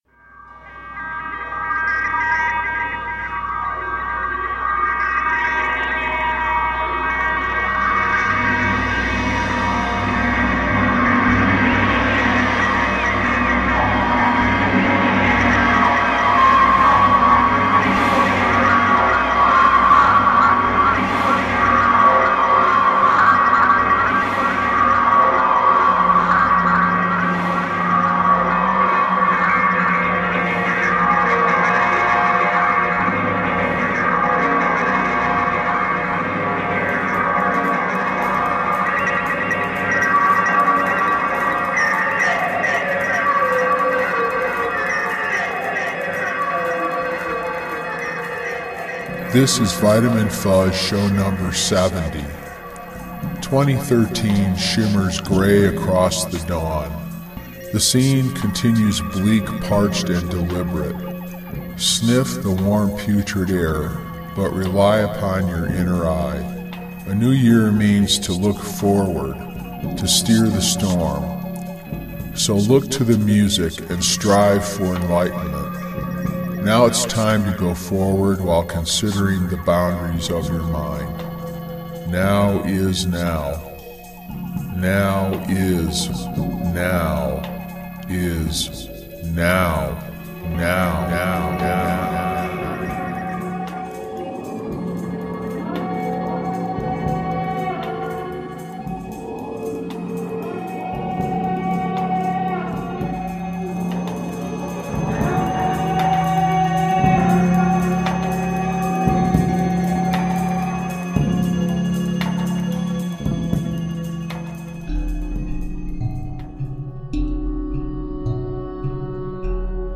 Vitamin Fuzz Radio Collective has been transporting you toward unexplored Psychedelic skylines since 2008 with broadcasts via our PODCAST or mp3 downloads.